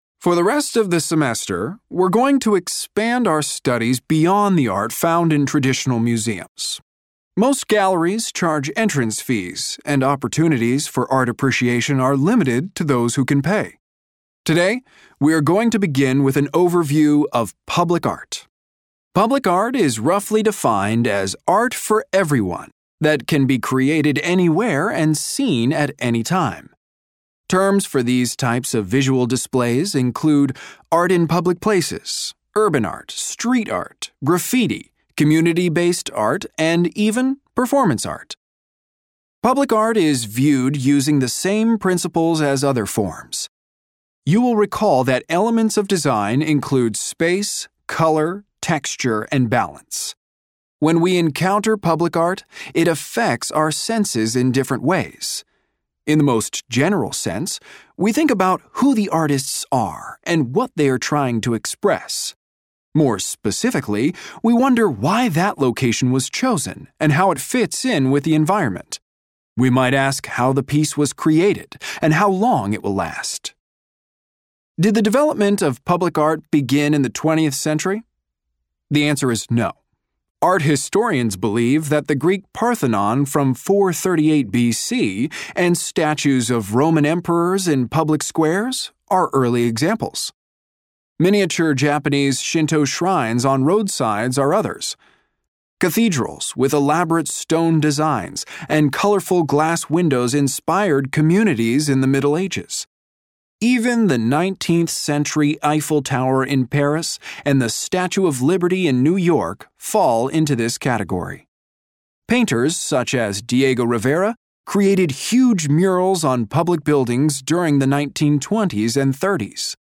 In this section, you will hear a monologue. You will hear the monologue twice.